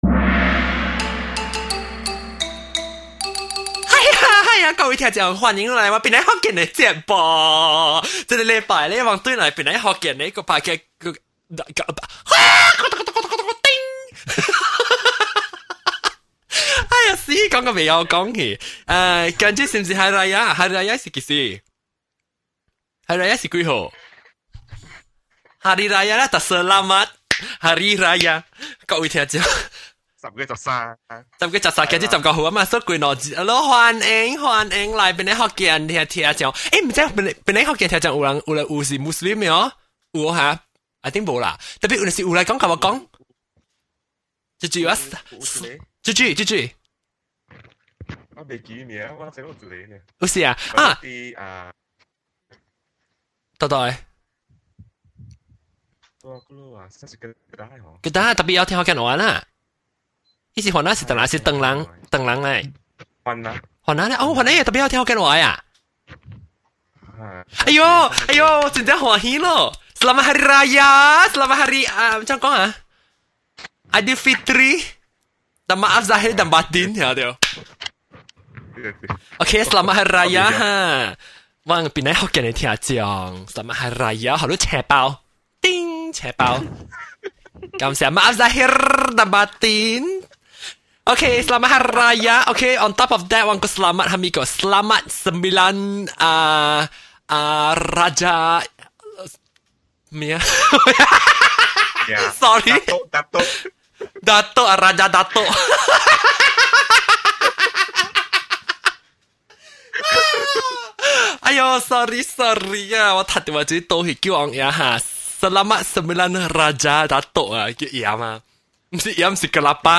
So, we have 9 people on the show. 8 guests plus 1 crazy host.